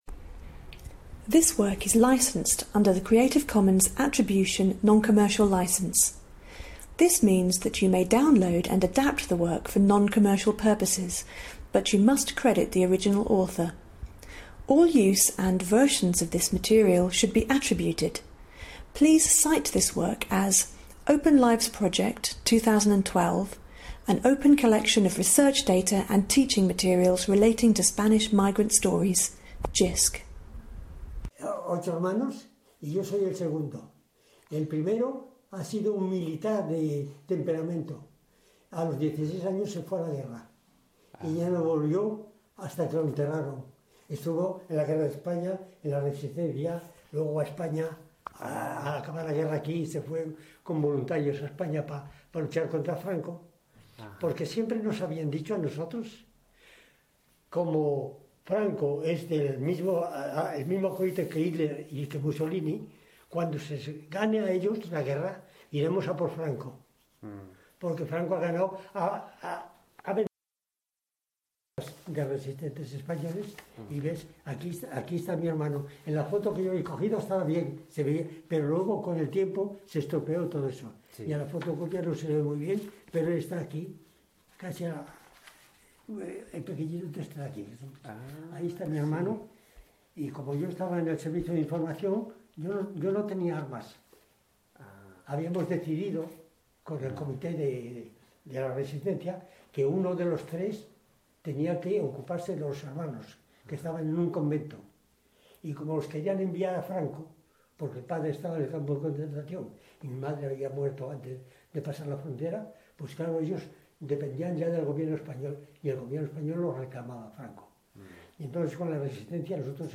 OpenLIVES Spanish emigre interviews